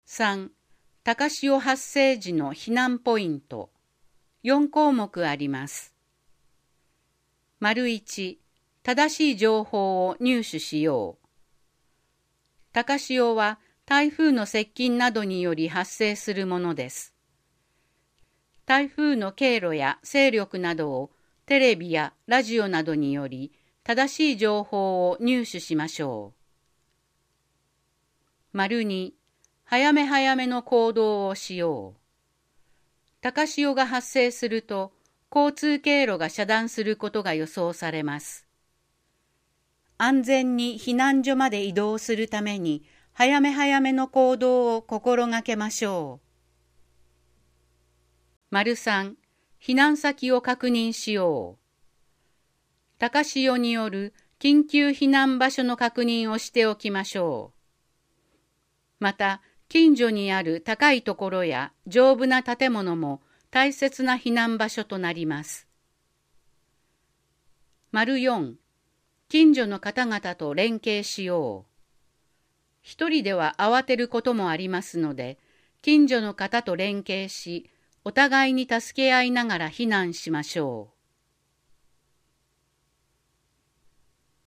豊中市総合ハザードマップ音訳版(1)1.災害を知る～3.大雨時のとるべき行動